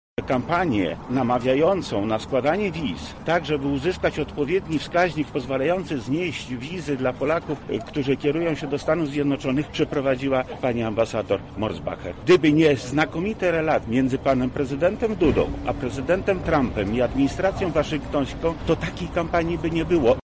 W lubelskiej siedzibie PiS przy ulicy Królewskiej została zorganizowana konferencja prasowa oraz spotkanie z wyborcami.
Kontestowane są choćby dobre relacje Polski ze Stanami Zjednoczonymi- mówił również wojewoda lubelski Lech Sprawka.